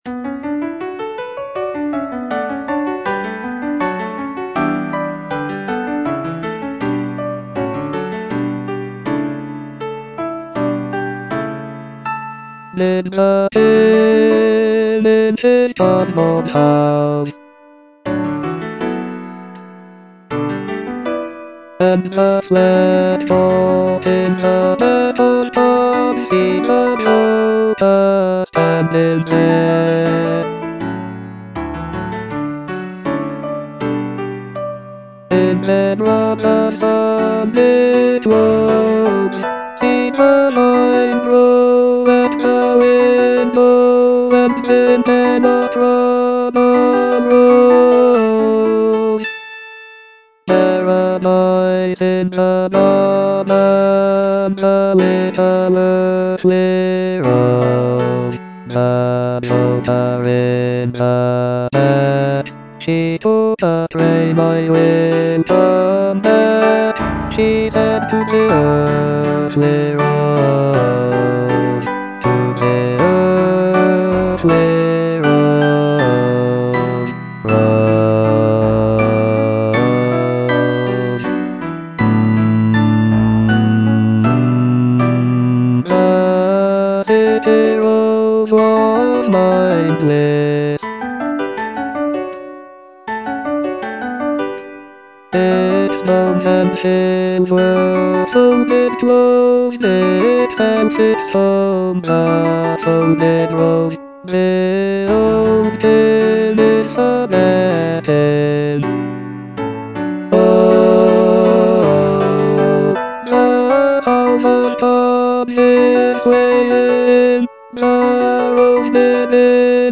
Bass (Voice with metronome)
is a choral work